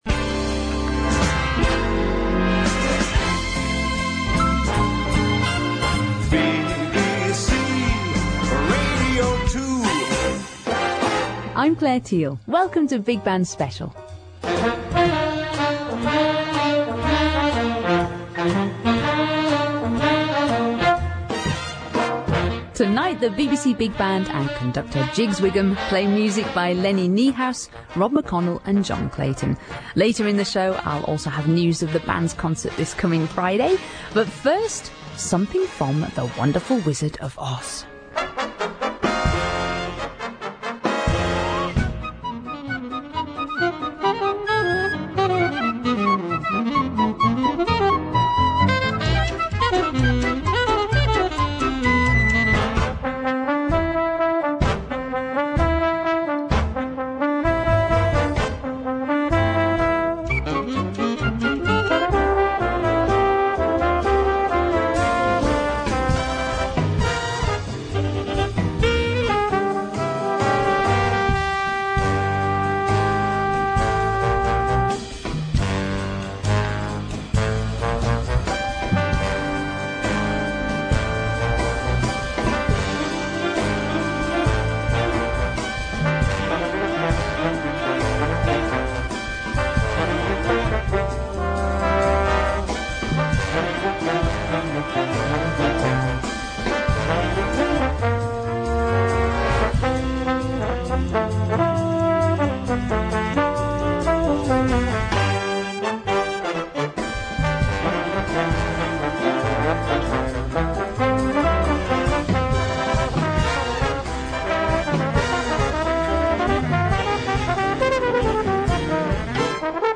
Baritone Saxophone